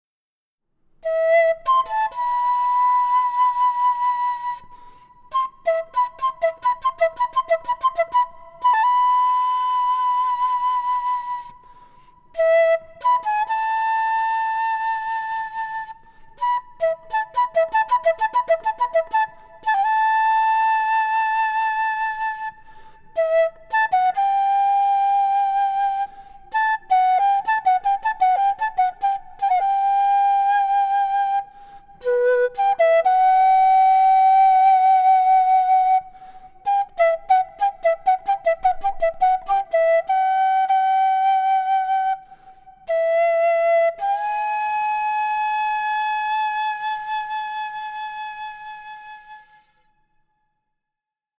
Кена (Quena, Jacaranda, Ramos, G) Перу
Материал: жакаранда.